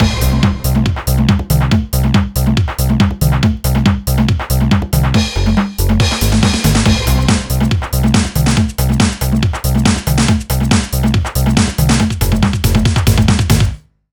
LINN DRUMS.wav